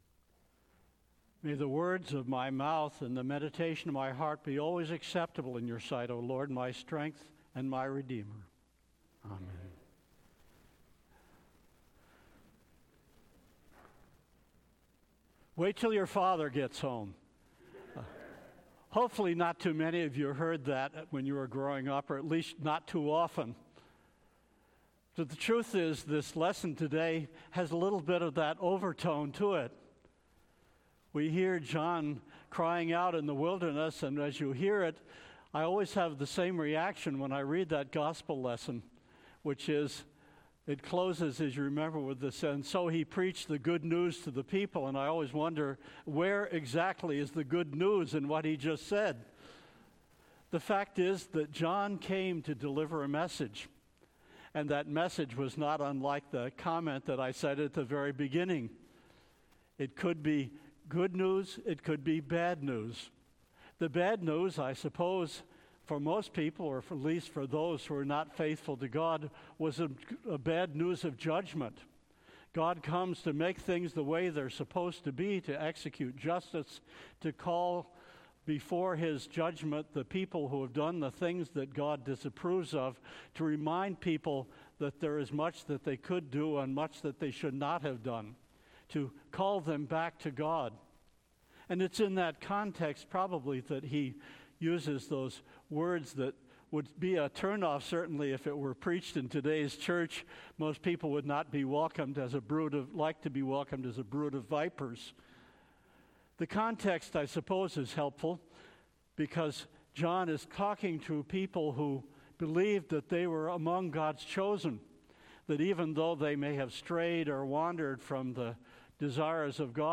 Sermon – December 16, 2018 – All Saints' Episcopal Church